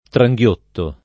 trajgLott&Sko], -sci (raro tranghiotto [trajgL1tto]) — voce ant. per «inghiottire»: brama, Ch’i popoli tranghiotte interi interi [br#ma k i p0poli trajgL1tte int%ri int%ri] (Soldani); Sotto il gorgo che tranghiottisce i forti E i lor vascelli [